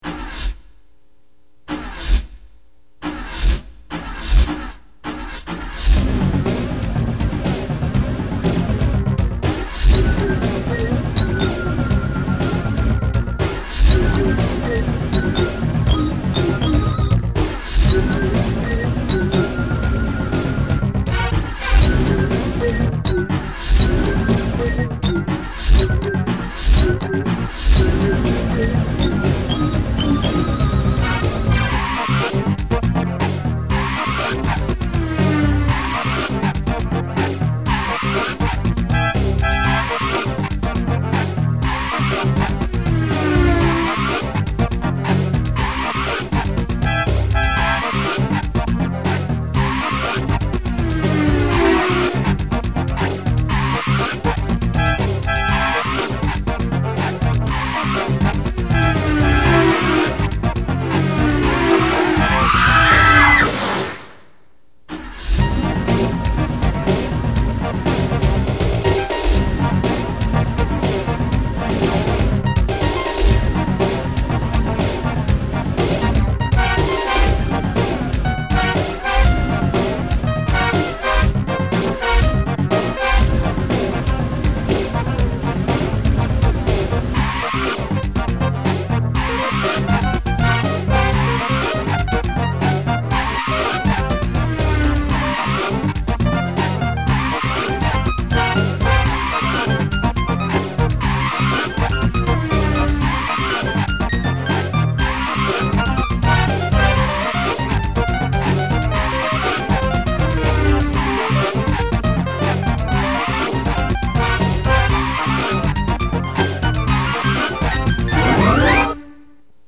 Synthesizer